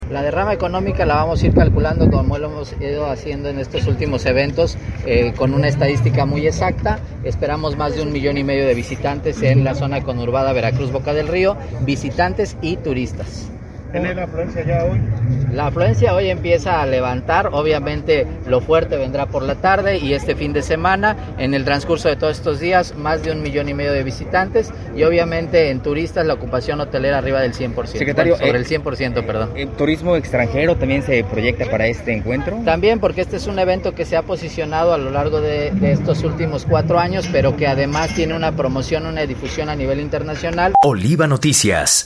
En entrevista, precisó que la afluencia comenzó a incrementarse desde antes del arribo de los veleros de los diferentes países, pero esto podría será mayor por la tarde y fin de semana, además dijo que esperan un alto flujo turístico, ya que se ha tenido una difusión a nivel nacional e internacional.
Al acudir a recibir a los veleros dijo que el fin de semana será el de mayor repunte por lo que seguramente habrá cien por ciento de ocupación hotelera.